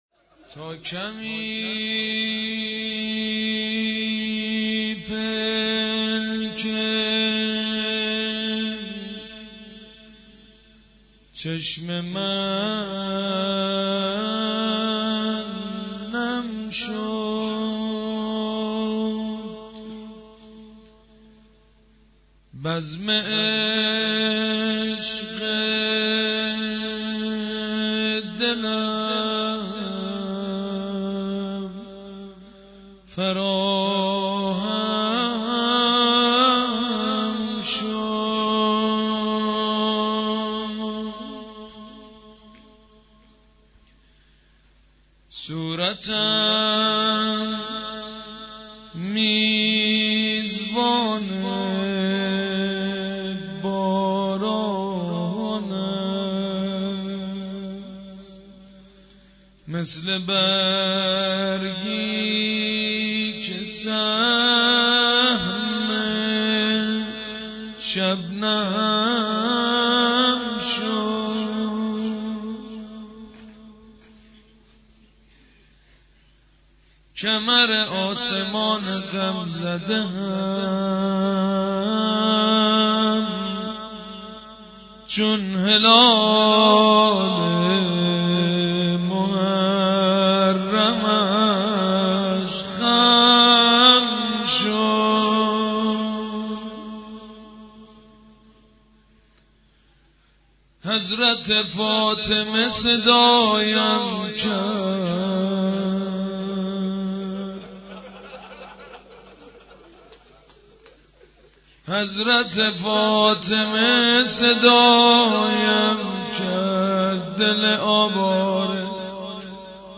شب اول محرم 93